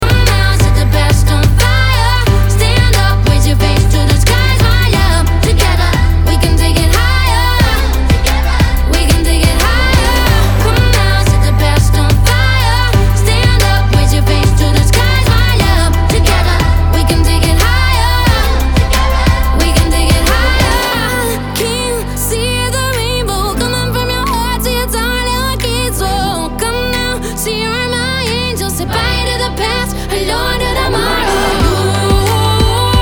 поп
громкие
женский вокал
веселые
Веселая нарезка на звонок